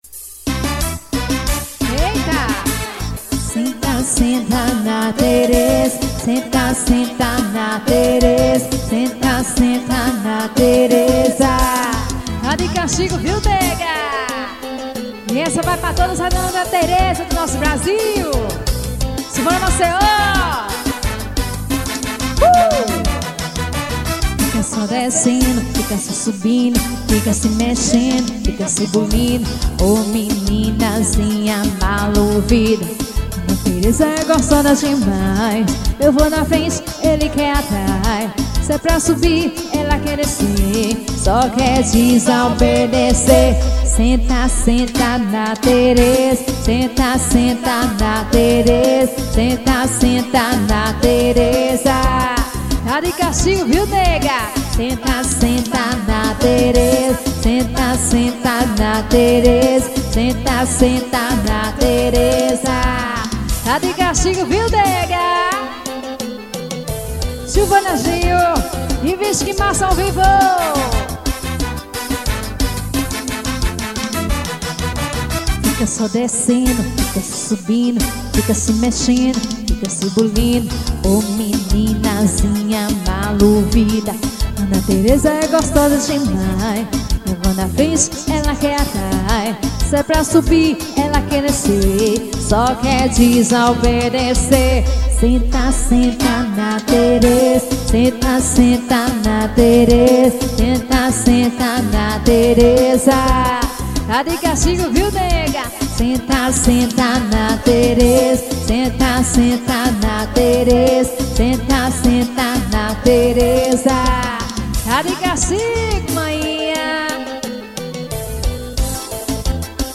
cd ao vivo.